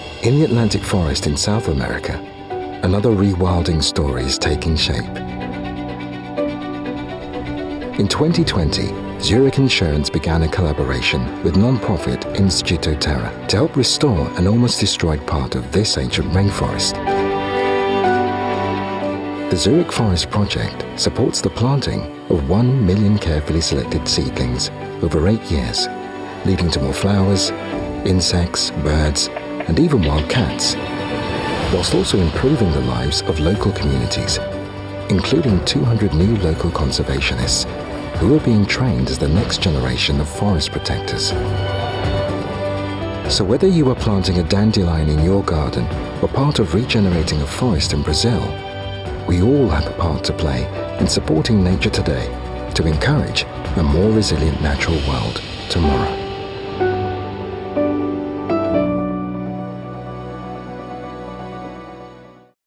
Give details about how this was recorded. Sennheiser MKH 416 / Shure SM7B